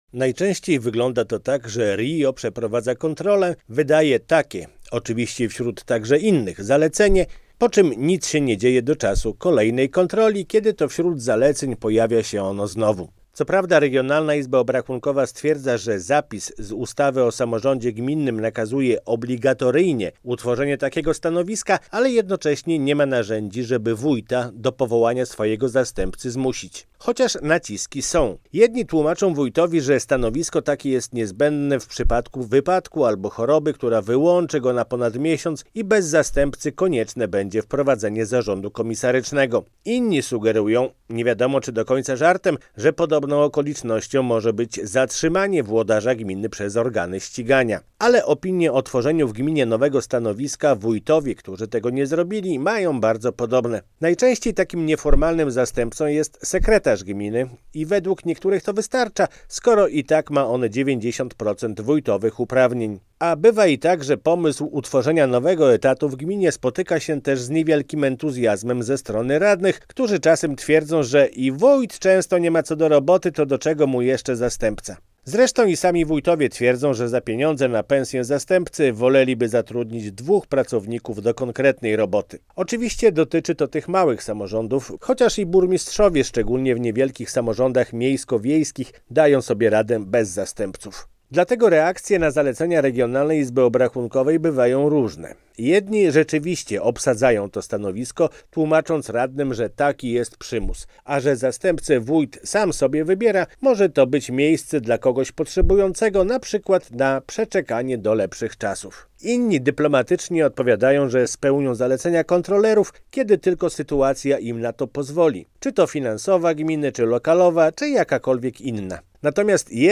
Radio Białystok | Felieton | Zastępca obowiązkowy